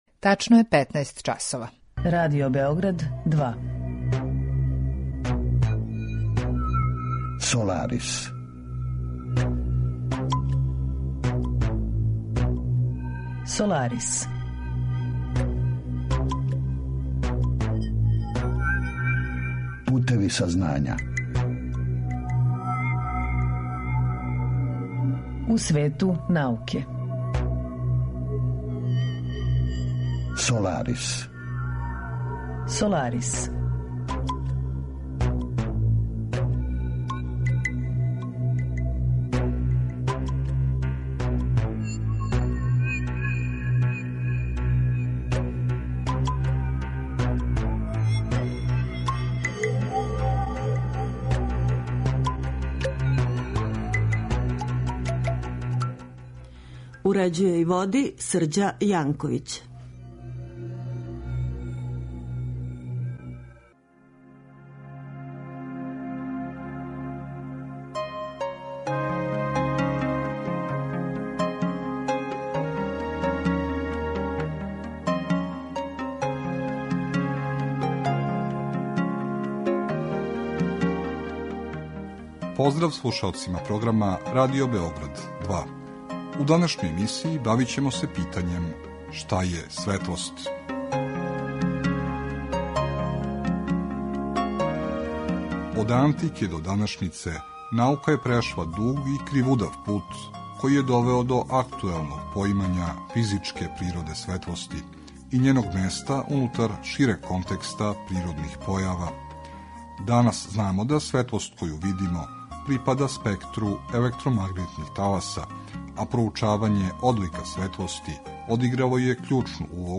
Разговор је први пут емитован 5. августа 2018.